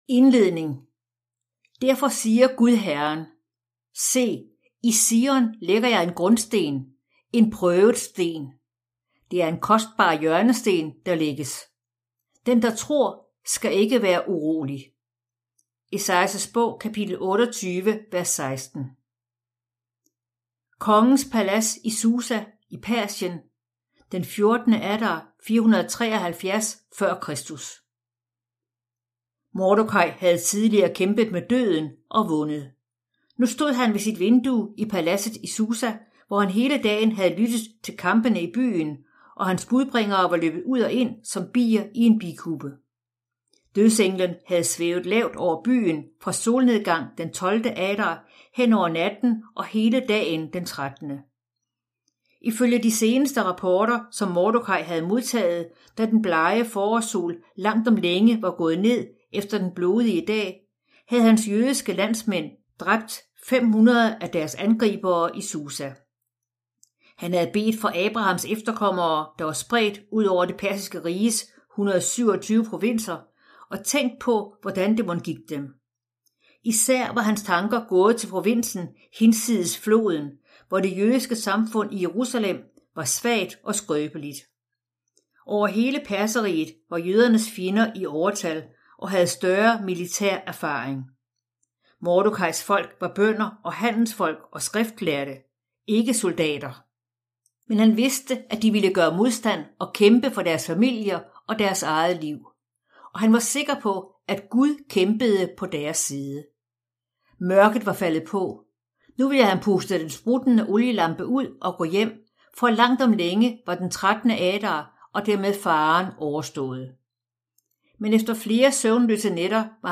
Hør et uddrag af Frihedens fundament Frihedens fundament Format MP3 Forfatter Lynn Austin Bog Lydbog E-bog 249,95 kr.